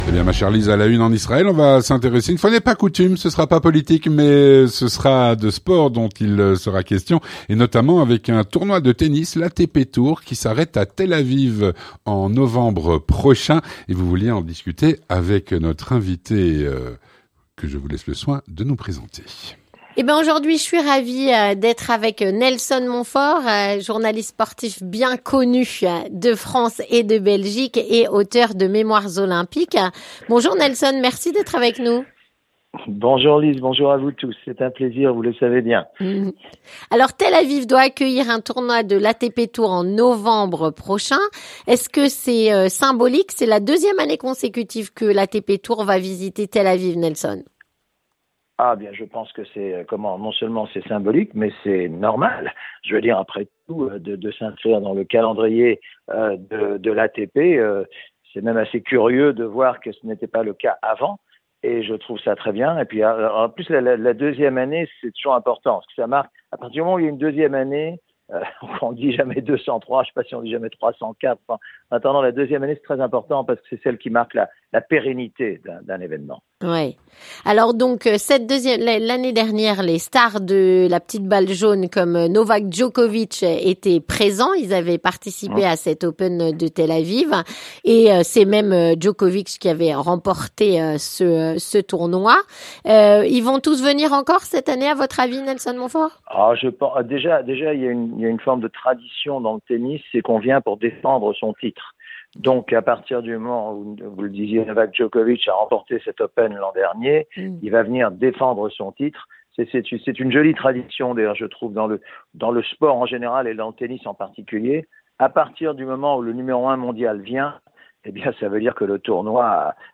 Avec Nelson Monfort, journaliste sportif et auteur de "Mémoires olympiques"